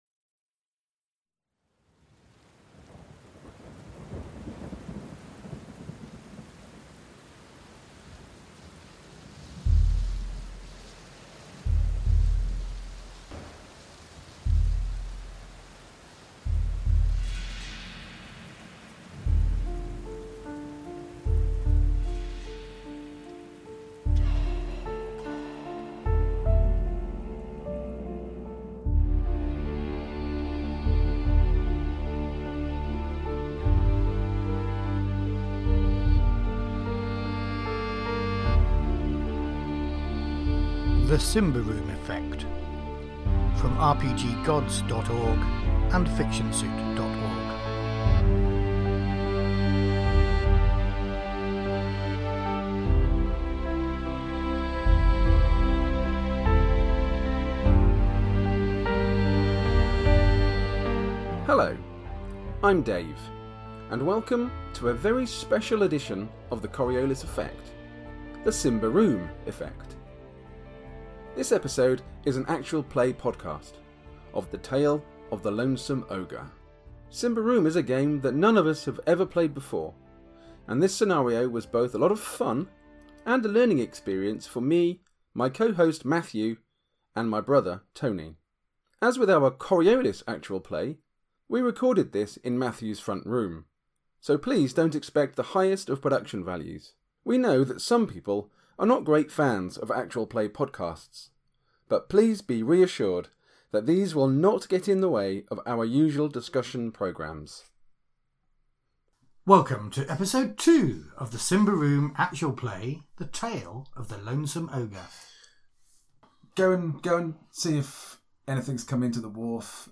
The second part of our Symbaroum Actual Play. Rado and Potboy journey to the Mills to investigate the lack of supply.